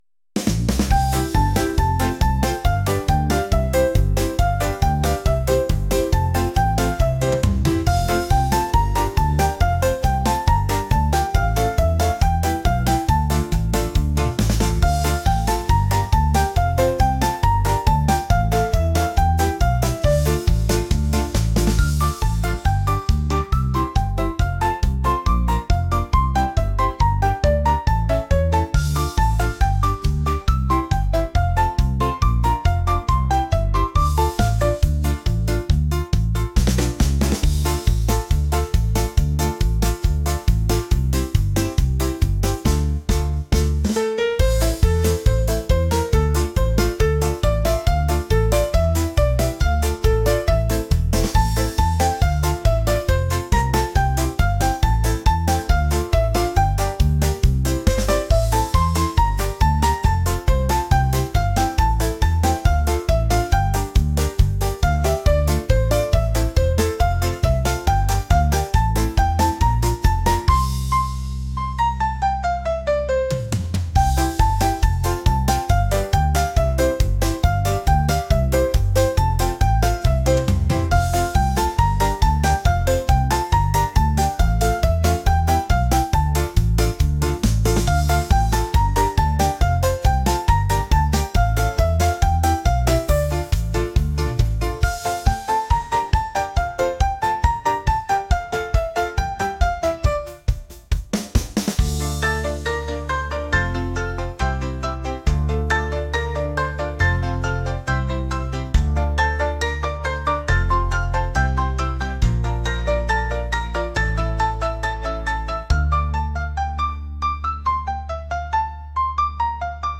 pop | energetic